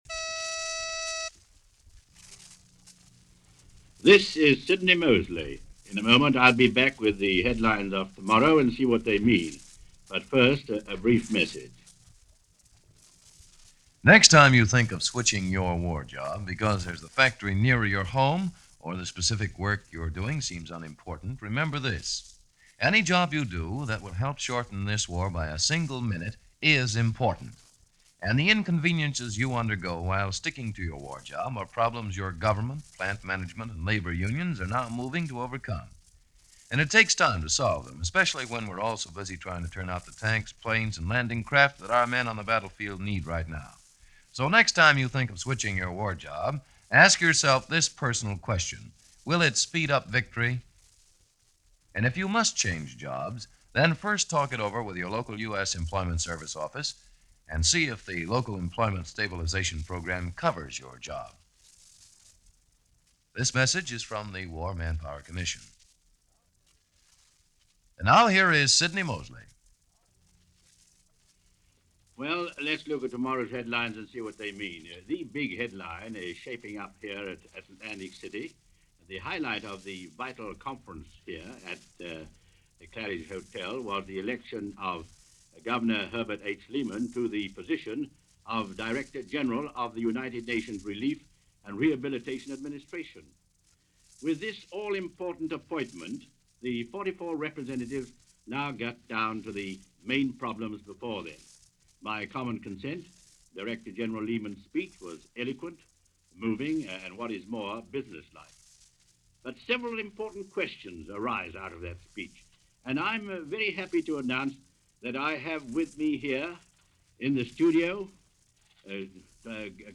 November 12, 1943 - Anticipating A United Nations - Anticipating Repairing A Broken World. An Interview With Herbert Lehman